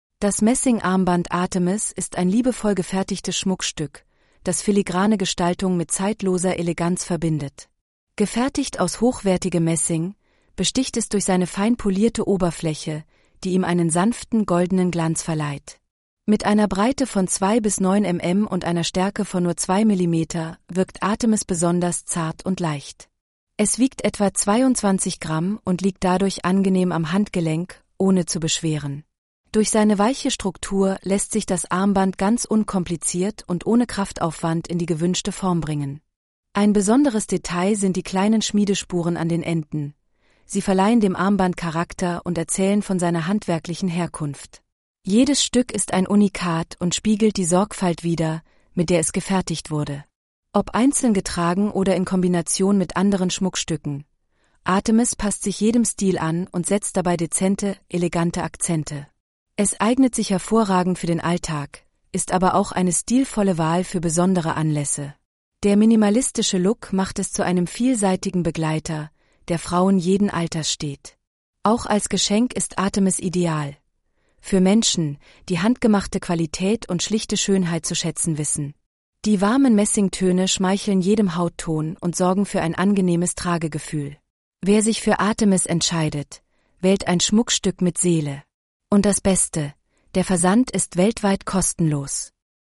artemis-ttsreader.mp3